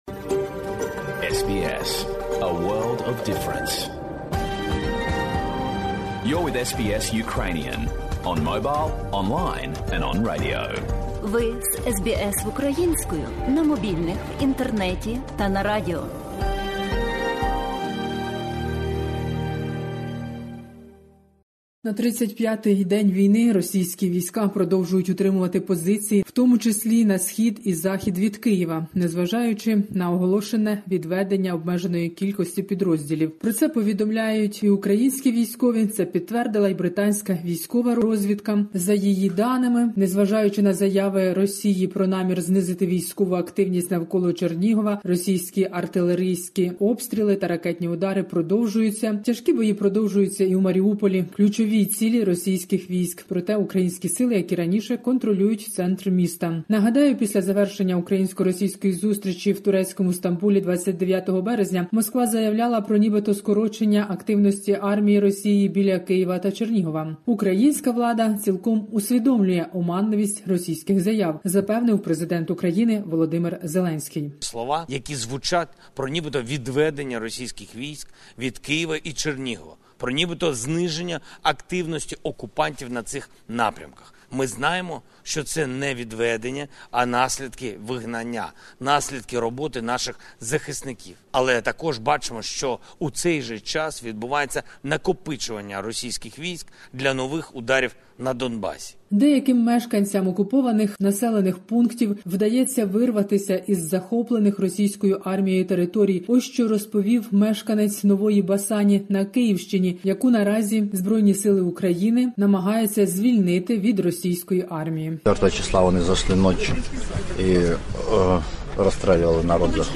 Добірка новин із воюючої України. Війна не стихає і Президент України наголошує на тому, що Росія нарощує війська на Донбасі.